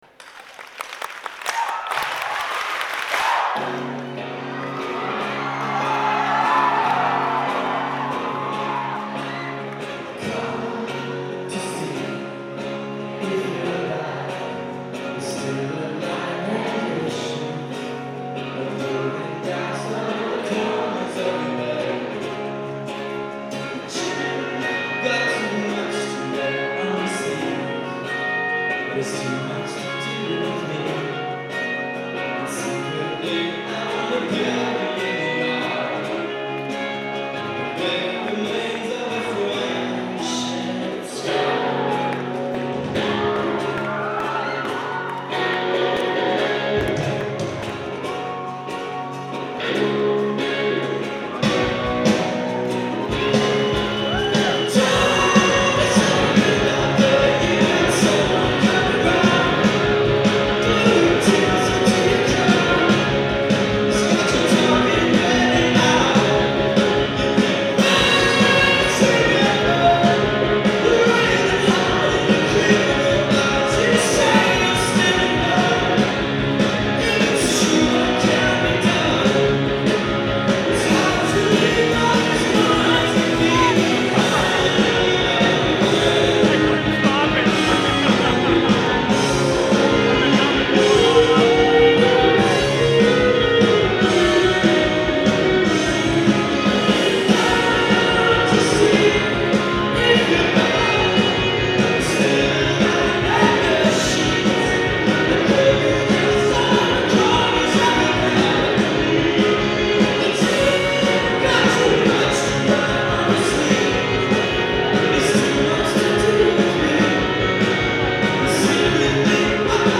Live at the Orpheum
in Boston, Mass.